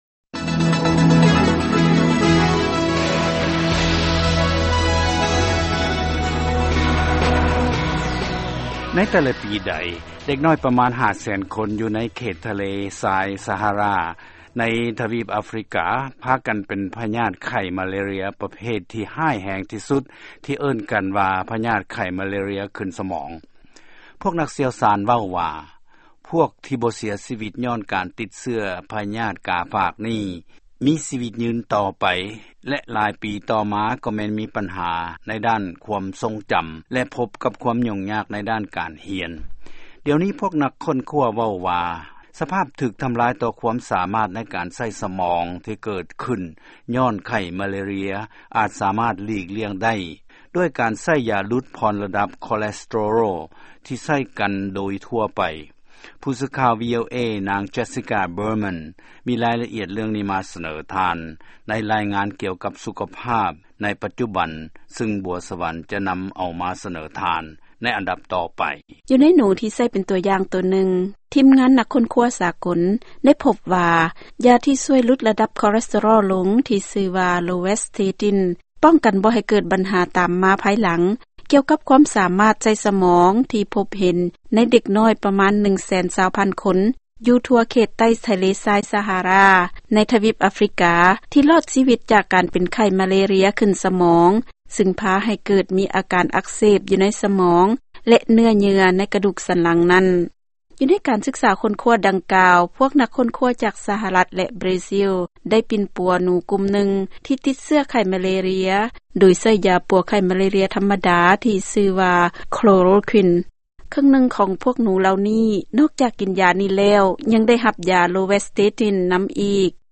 ຟັງລາຍງານກ່ຽວກັບຢາປົວໄຂ້ມາເລເຣຍ